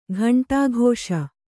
♪ ghaṇṭāghōṣa